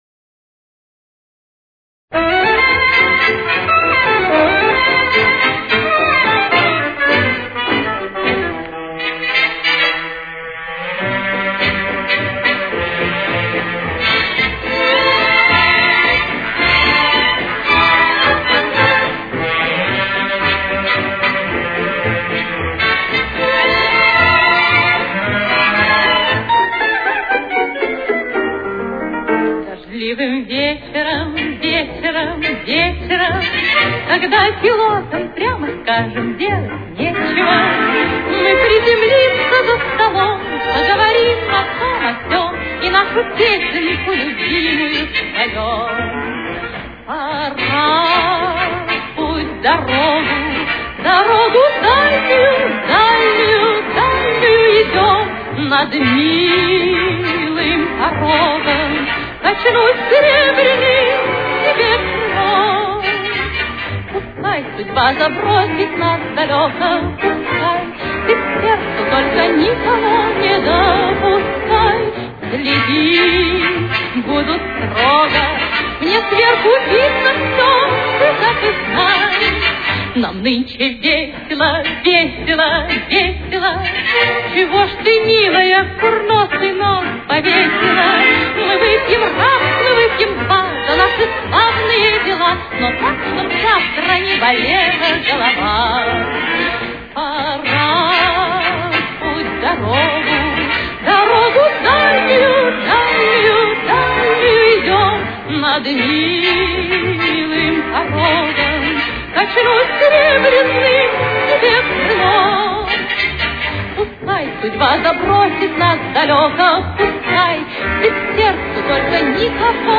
с очень низким качеством (16 – 32 кБит/с)
Си-бемоль мажор. Темп: 119.